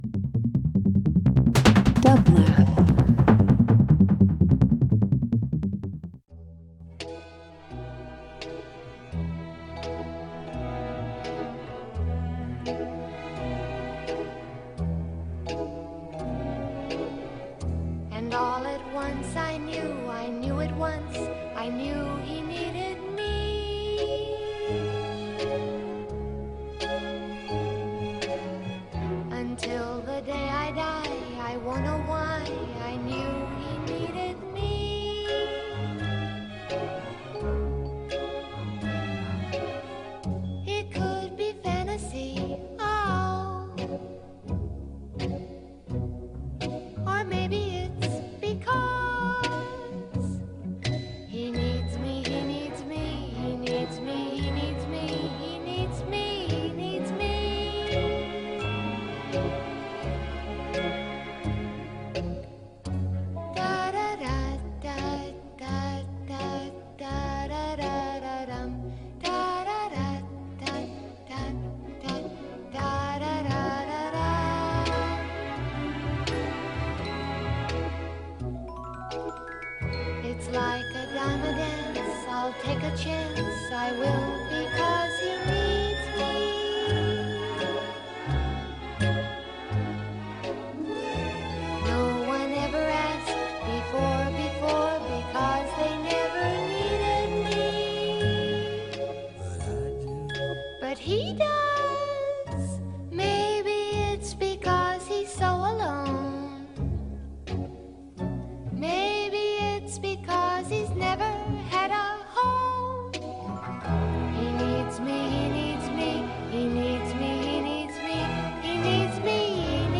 Dream Pop Electronic Rock Shoegaze
Her music selects are nostalgic, dissociative and fun :)